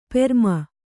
♪ perma